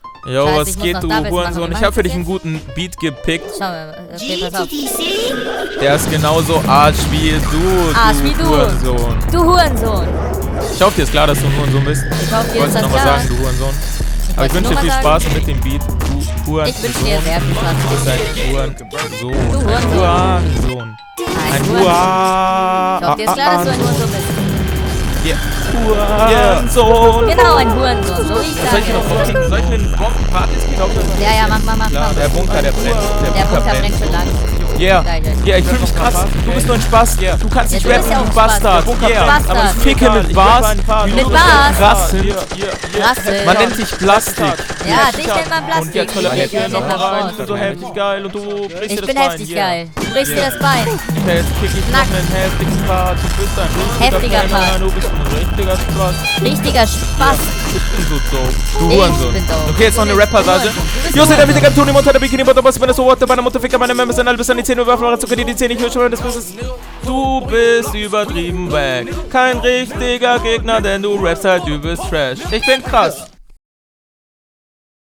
Geiler Beat.
Mochte den Doubletime.